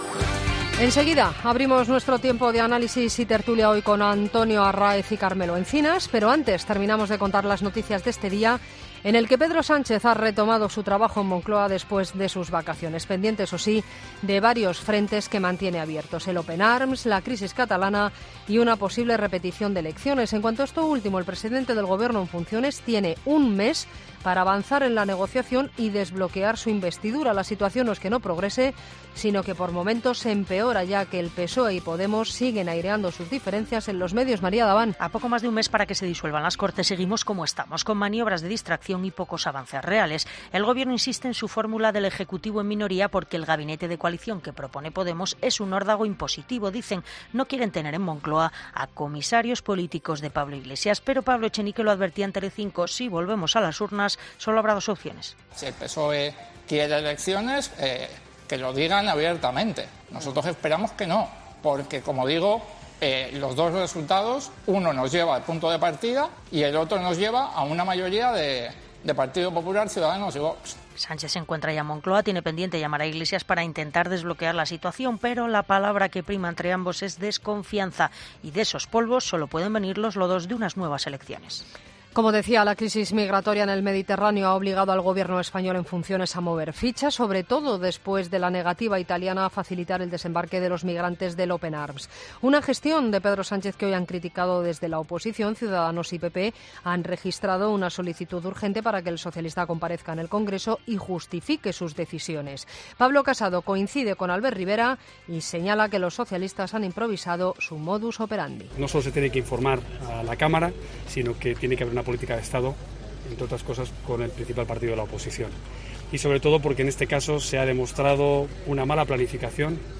Boletín de noticias COPE del 21 de agosto de 2019 a las 23.00 horas